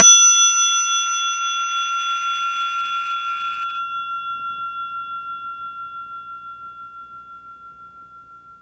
question_markTermékkör Pengetős csengő
55 mm átmérőjű pengetős réz csengő extra hosszú lecsengési idővel